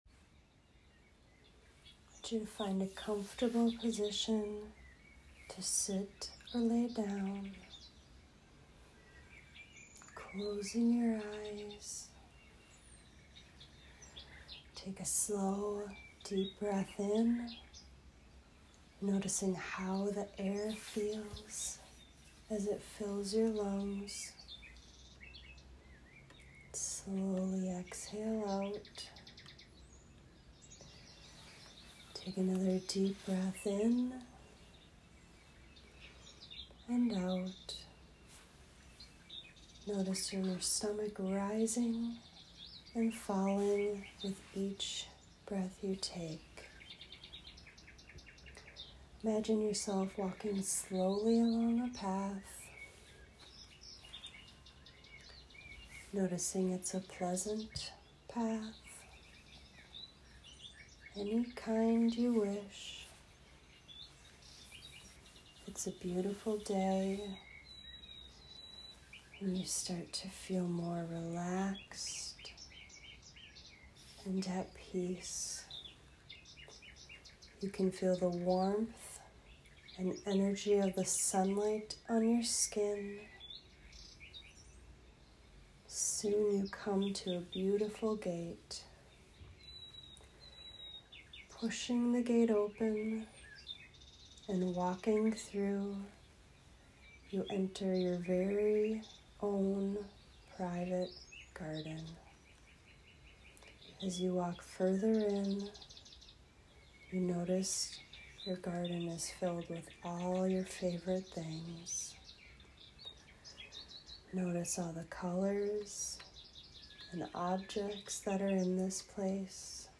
Garden Imagery Guided Meditation - Wild Tree Wellness